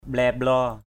/ɓlɛ-ɓlɔ:/ (đg.) thập thò = avancer et reculer. forward and backward. mblaiy-mblaow di lakuk bambeng =O*Y-=O<| d} lk~K bO$ thập thò sau cánh cửa. forward and backward behind the door....